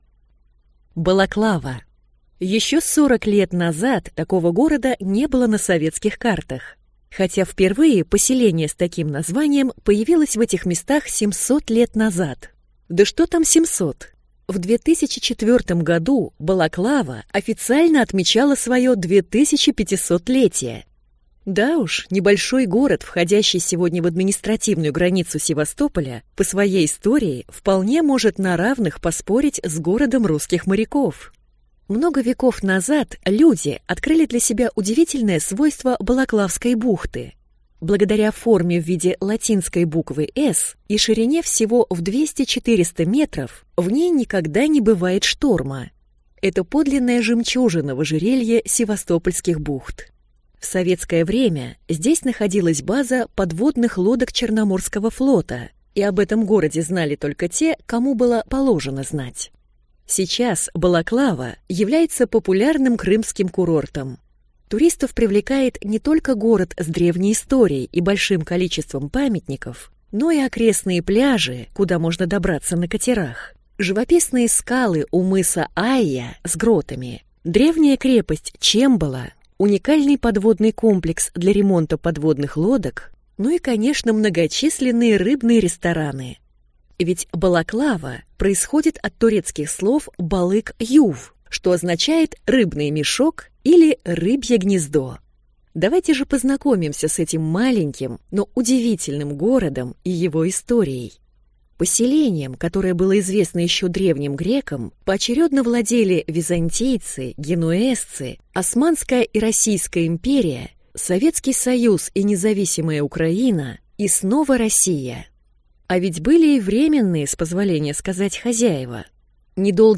Аудиокнига Балаклава | Библиотека аудиокниг